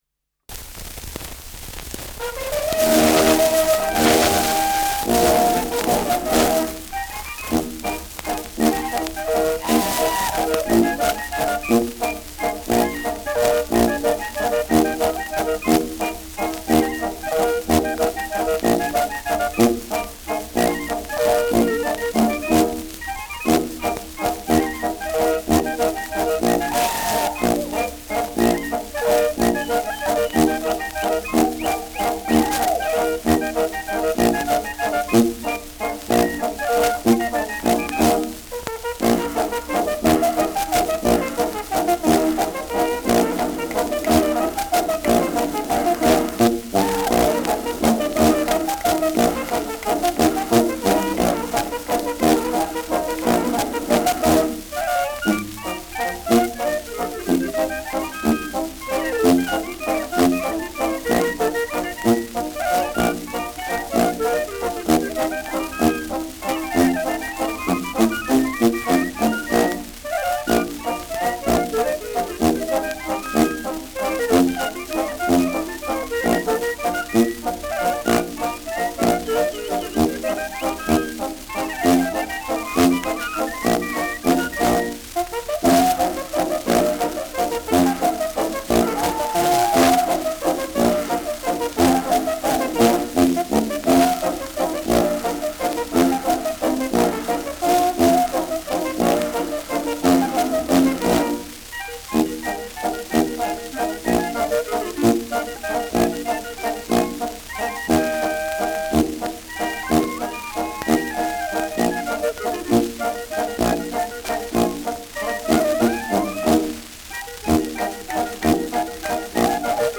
Schellackplatte
präsentes Rauschen : präsentes Knistern : abgespielt : „Schnarren“ : leiert
Dachauer Bauernkapelle (Interpretation)
Mit Juchzern, Pfiffen,Klopfgeräuschen.